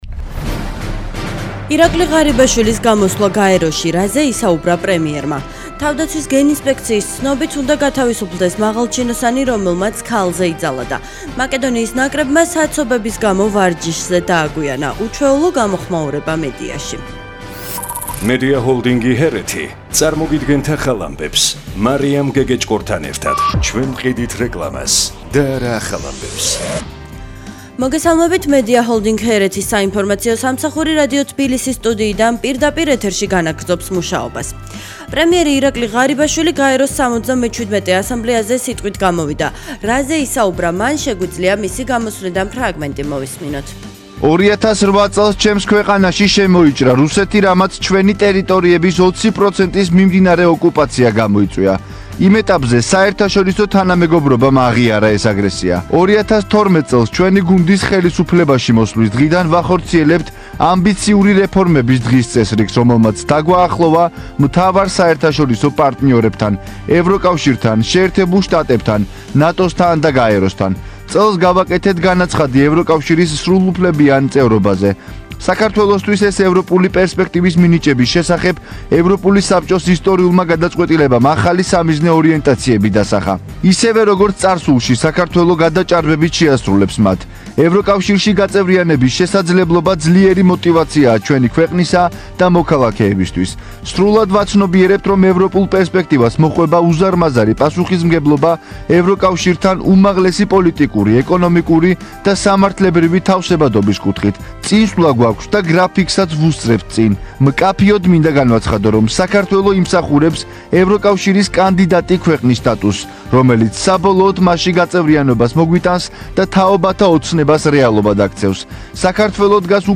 ახალი ამბები 10:00 საათზე